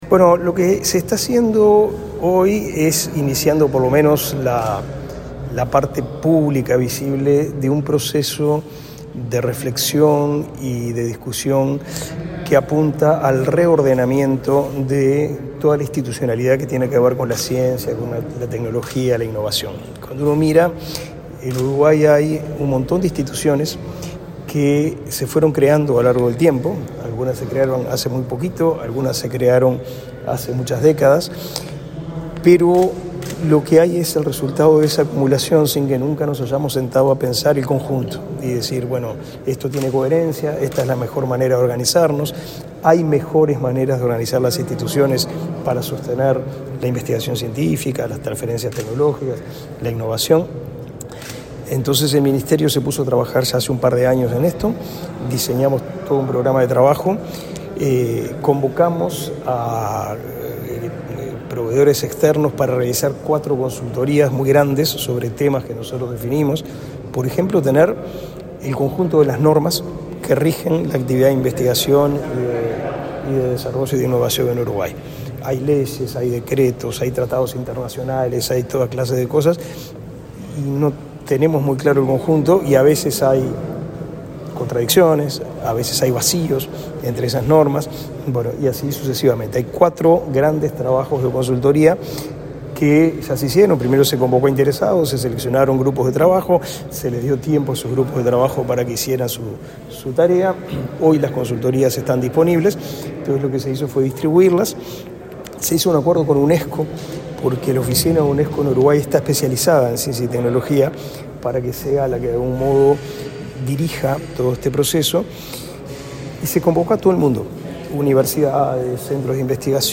Declaraciones del ministro de Educación y Cultura, Pablo da Silveira
Declaraciones del ministro de Educación y Cultura, Pablo da Silveira 07/03/2023 Compartir Facebook X Copiar enlace WhatsApp LinkedIn El Ministerio de Educación y Cultura realizó, este martes 7 en el edificio Mercosur, un taller, en el marco del reordenamiento institucional del área de ciencia, tecnología e innovación. El titular de la cartera, Pablo da Silveira, dialogó con la prensa luego de la apertura del evento.